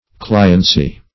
Cliency \Cli"en*cy\, n. State of being a client.